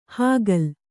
♪ hāgal